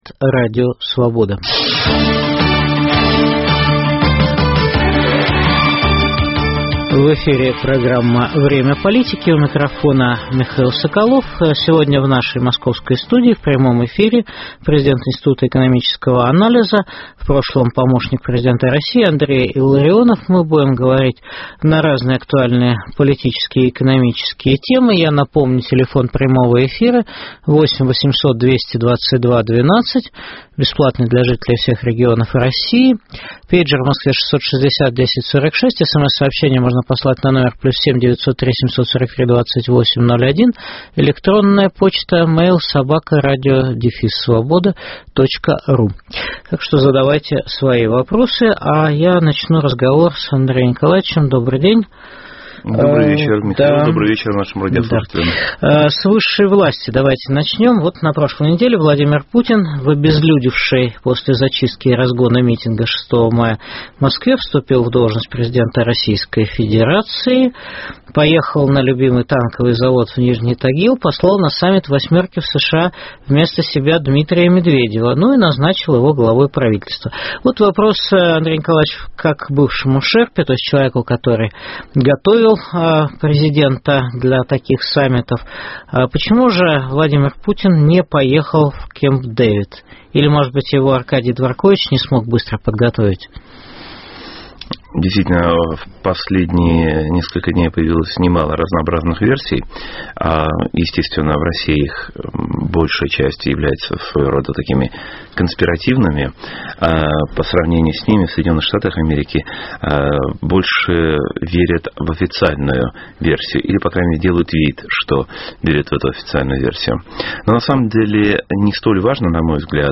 Какую альтернативу курсу президента Владимира Путина может предложить оппозиция? В программе выступит президент Института экономического анализа Андрей Илларионов.